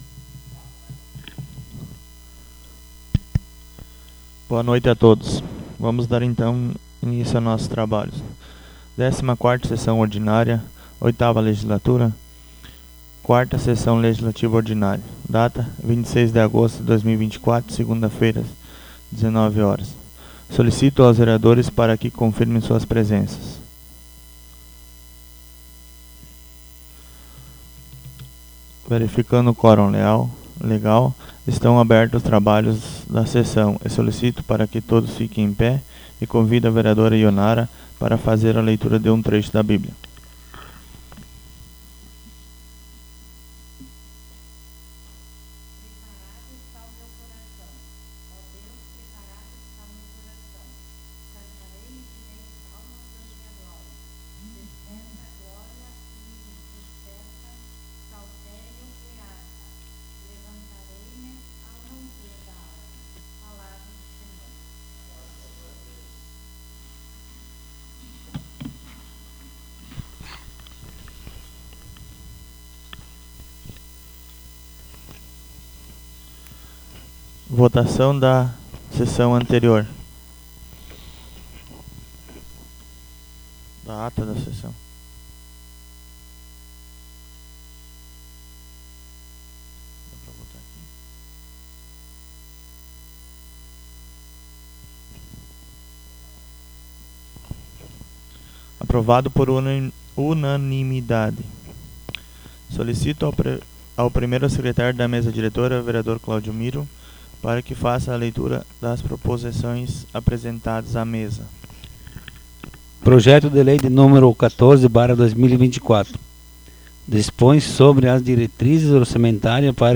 Audio da 14º Sessão Ordinária 26.08.24